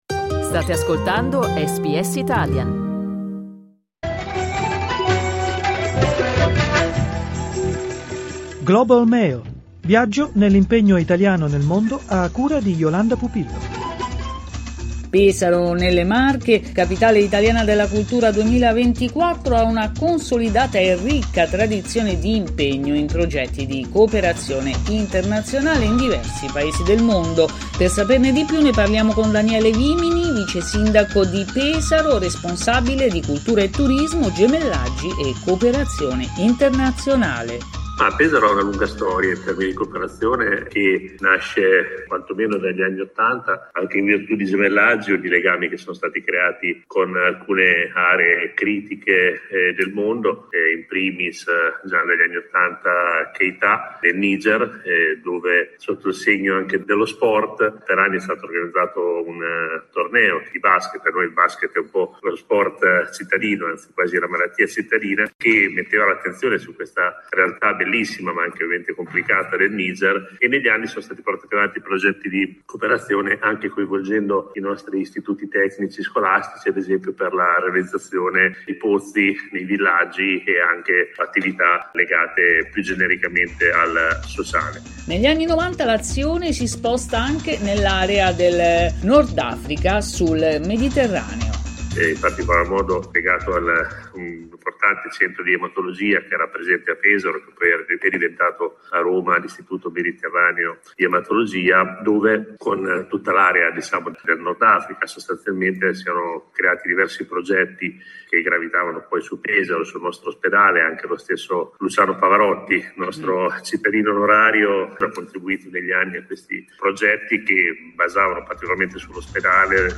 Ascolta l'intervista a Daniele Vimini cliccando sul tasto "play" in alto Daniele Vimini, vicesindaco di Pesaro.